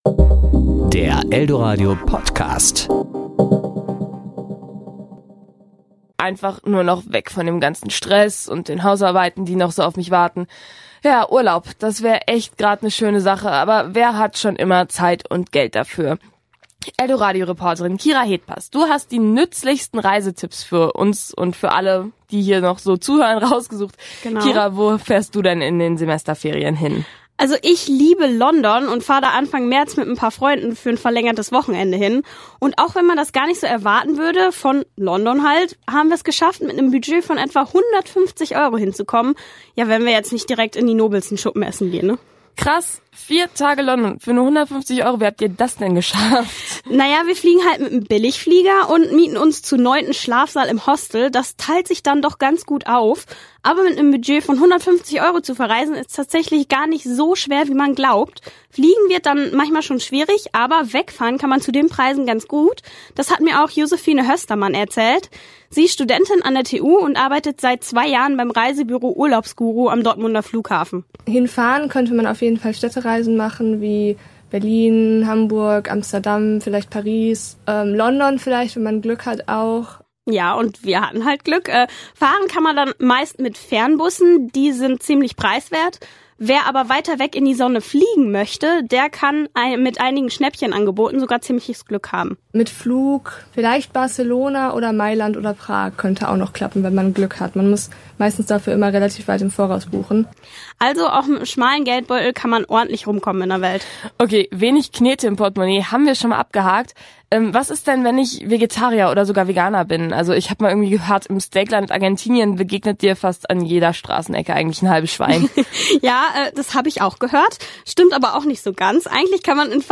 Serie: Kollegengespräch